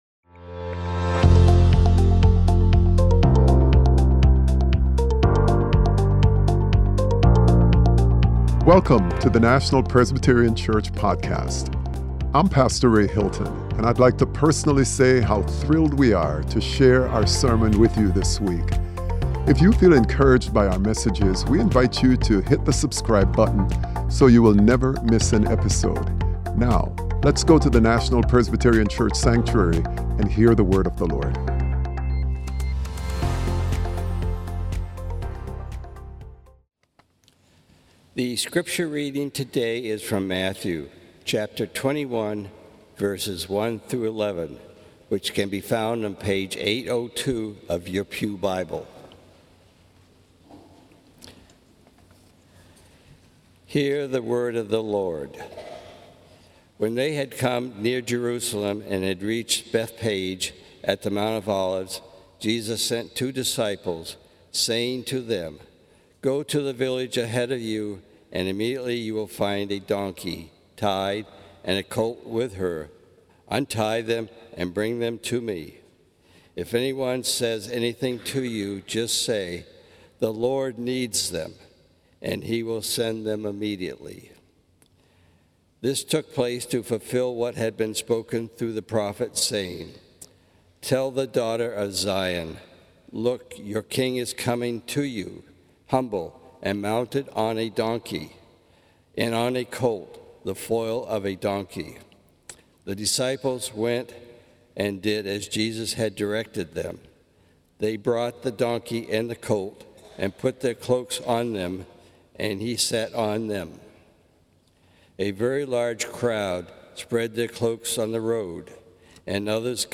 Sermon: Encountering God More Deeply - The King We Didn't Expect - National Presbyterian Church